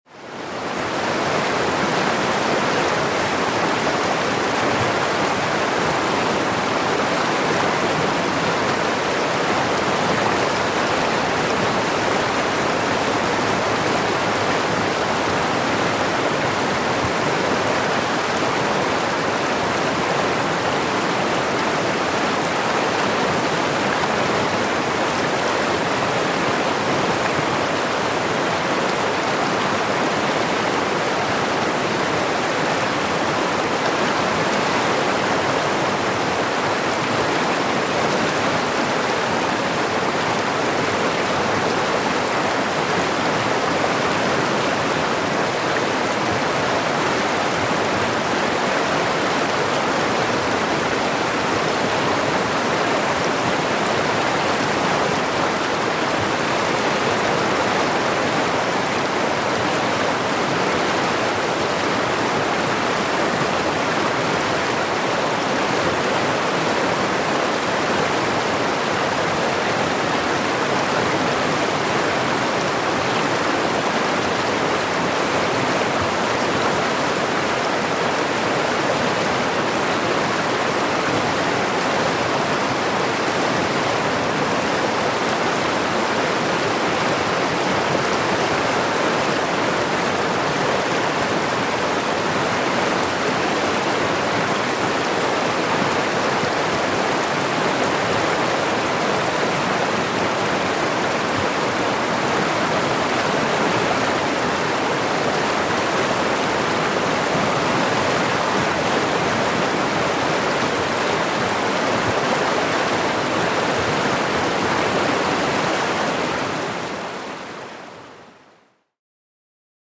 Water, Rapids | Sneak On The Lot